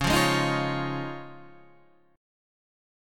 C# Major Add 9th